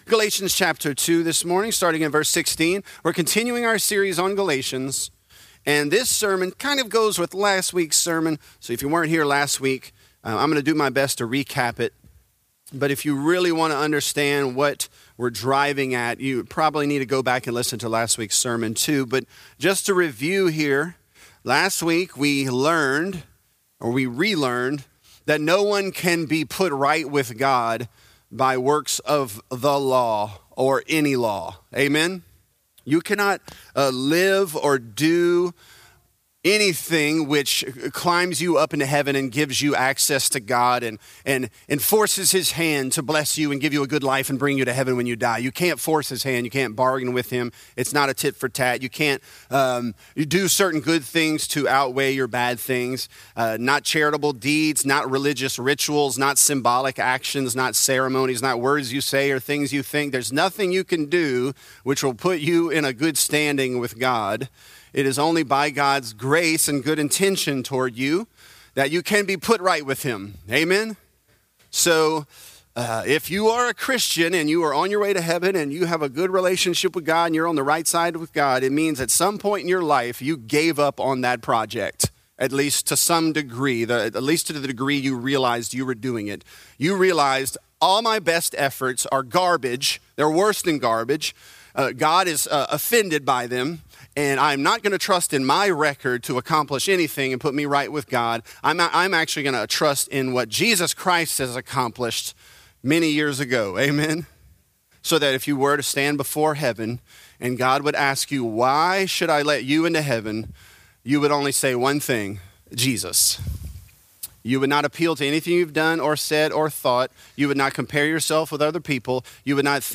Galatians: Using The Law Lawfully | Lafayette - Sermon (Galatians 2)